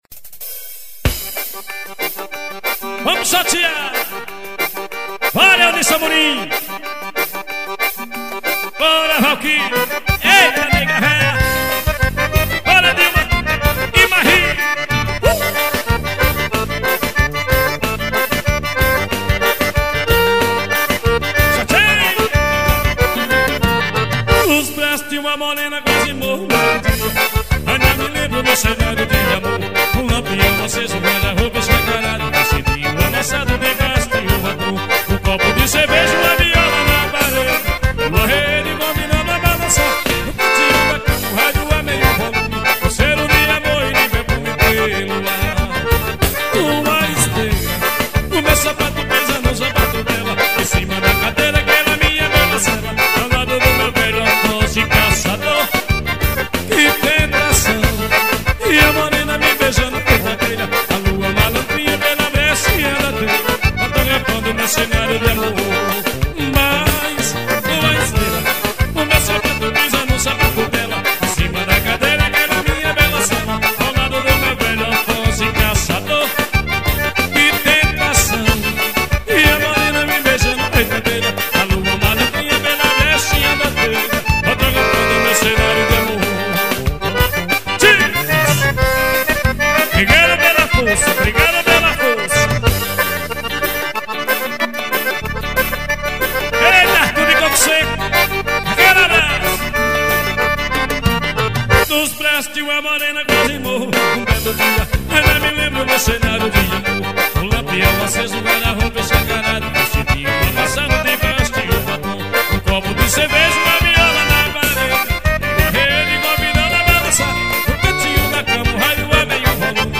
gravação de cd ao vivo.